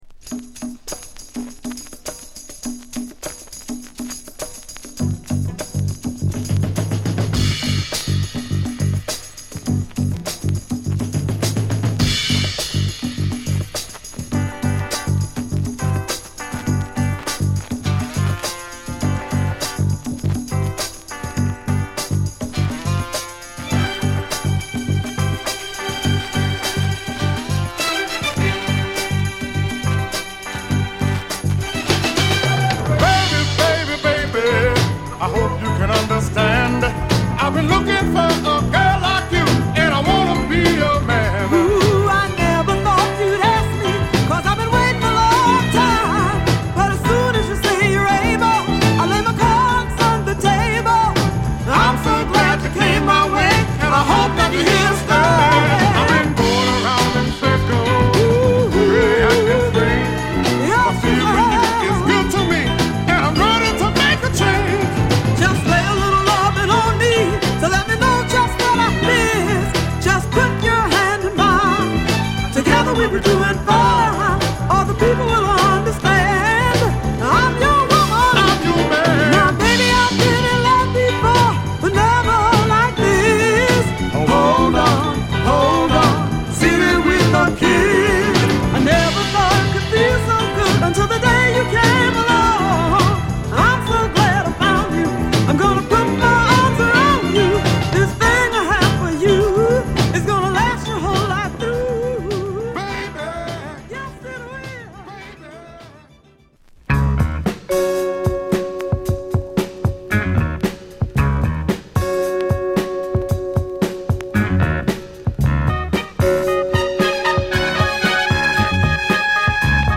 フィラデルフィアとデトロイトで録音された、マイナーレーベルからのデュオ！